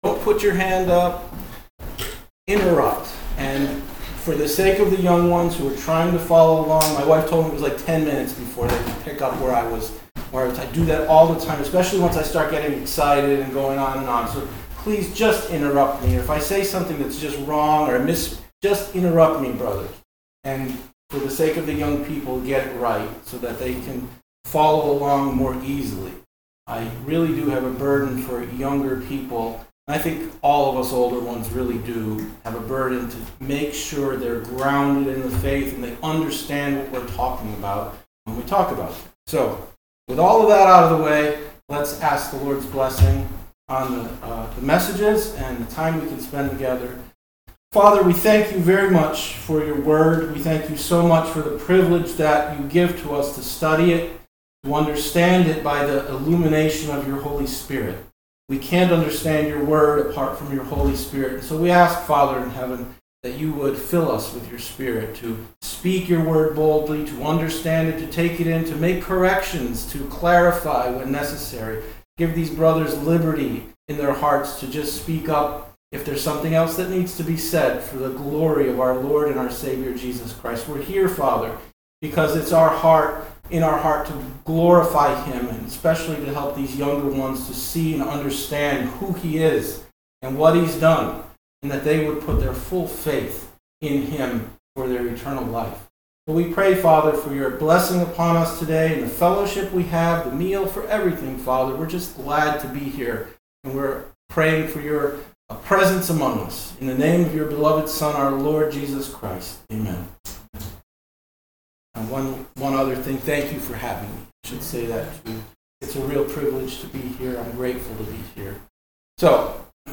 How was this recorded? Spring Conference P.1 Passage: Hebrews Service Type: Sunday Afternoon « 05.03.25